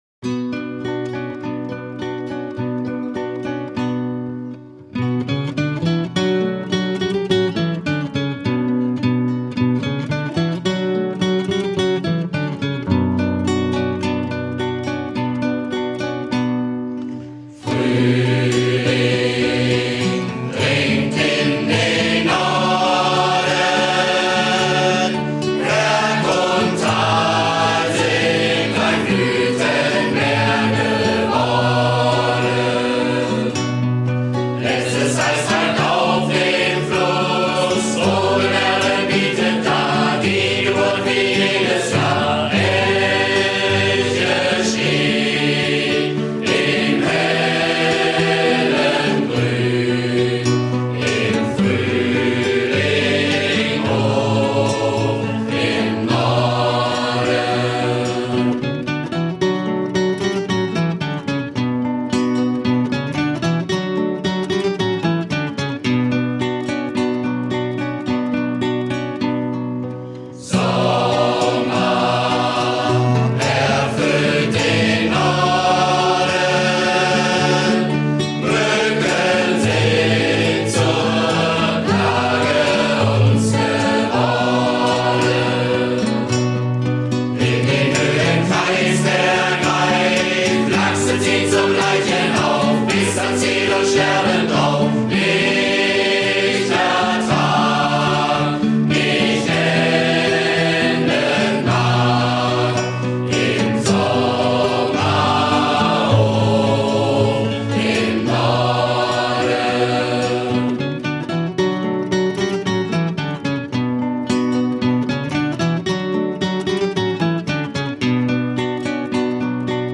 Gruppengesang